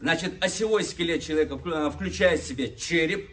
Nexdata/Russian_Spontaneous_Speech_Data at main